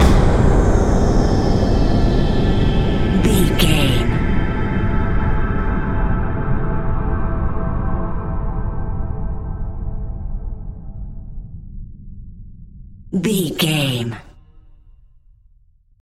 Atonal
ominous
disturbing
eerie
drums
synthesiser